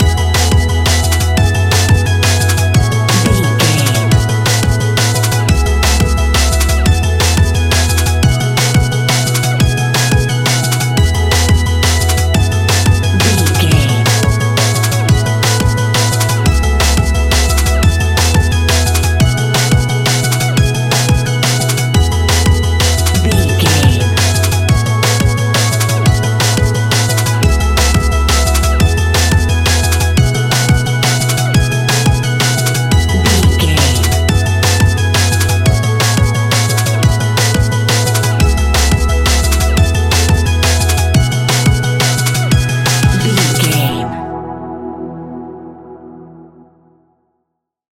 Ionian/Major
electronic
dance
techno
trance
synths
instrumentals